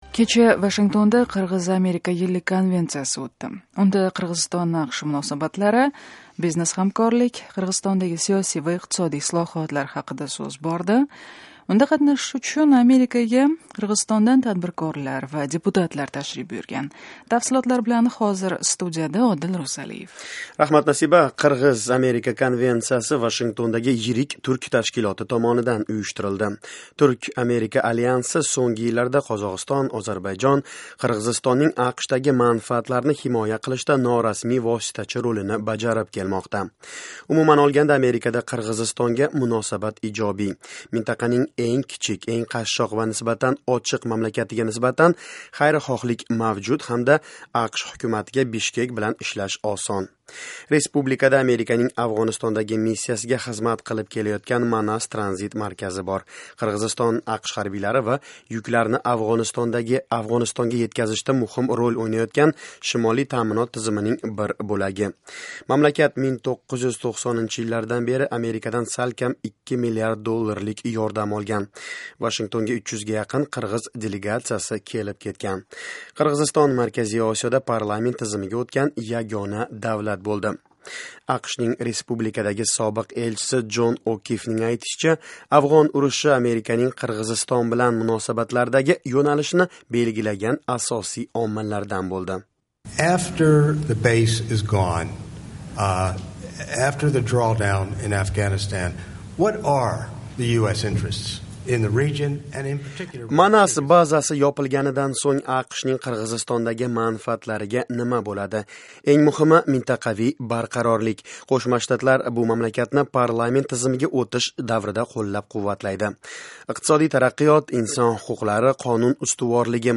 Qirg'iz-Amerika konvensiyasidan reportaj